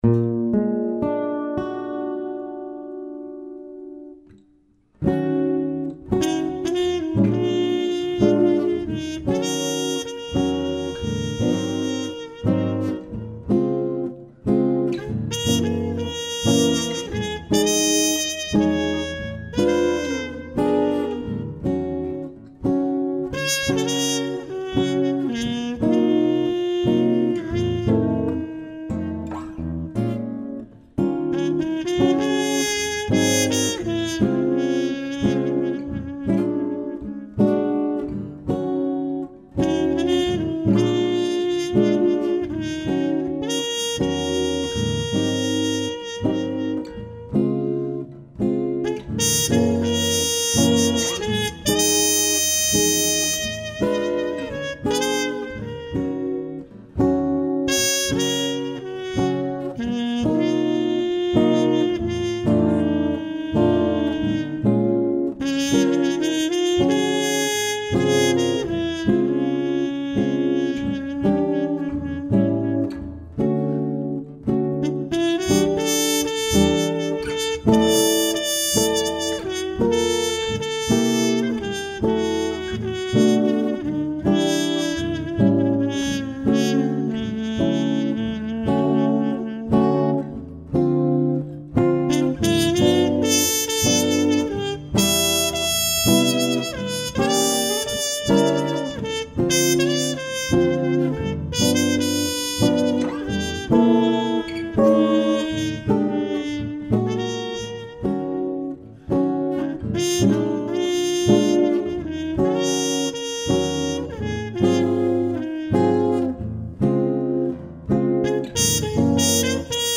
jazz standard